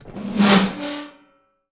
metal_scrape_deep_grind_squeak_04.wav